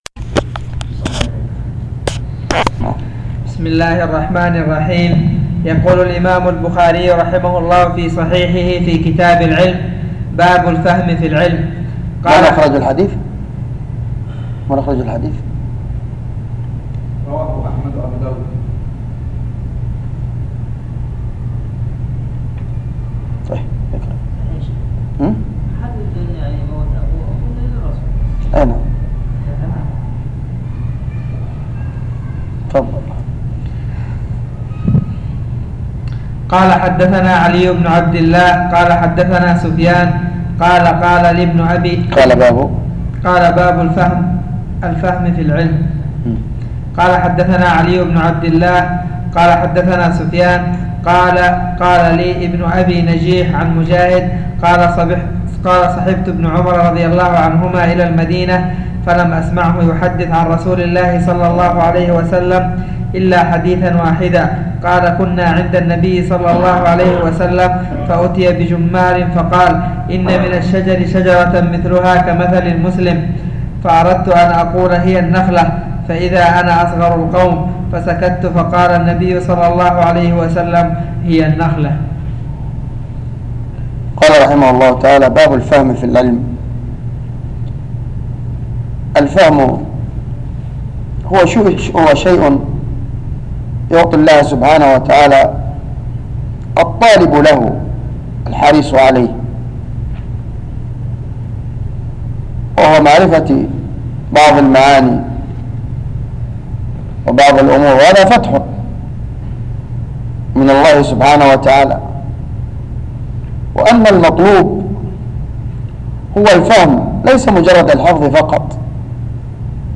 سلسلة دروس شرح صحيح البخاري - شرح مسجد الفتاح بضمد